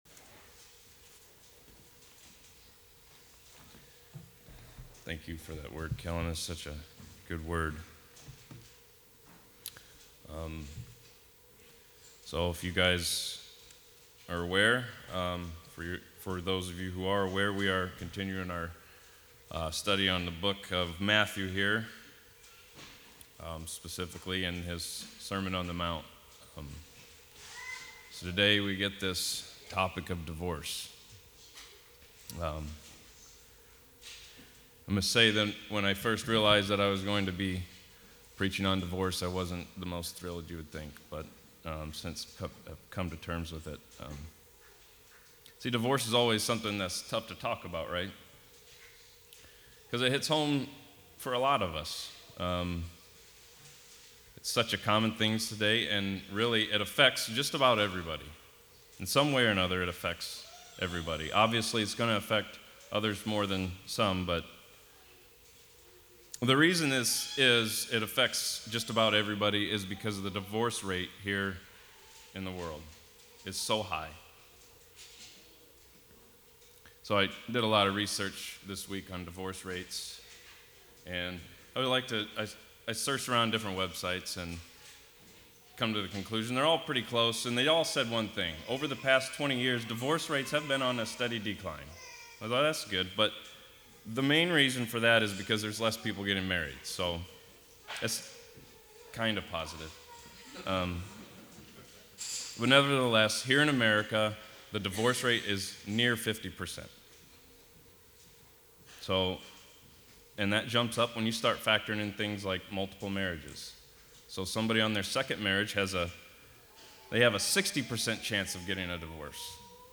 Covenant Reformed Fellowship Sermons